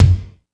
Mega Drums(01).wav